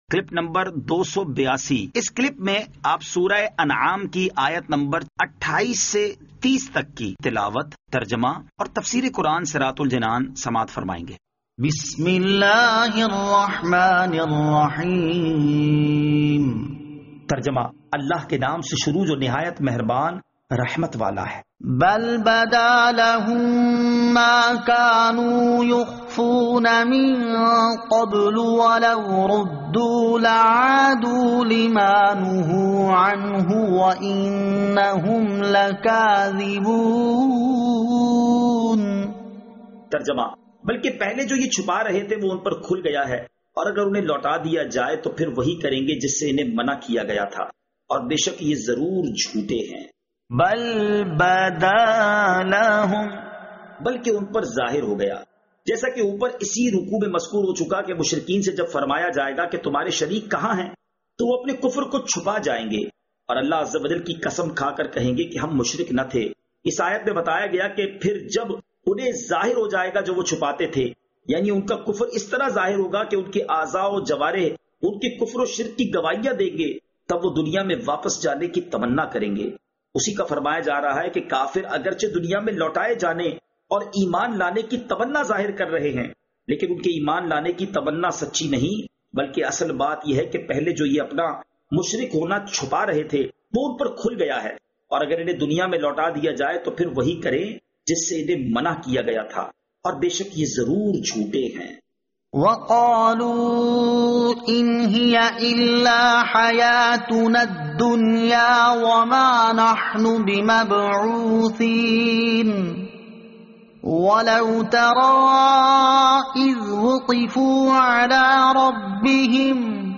Surah Al-Anaam Ayat 28 To 30 Tilawat , Tarjama , Tafseer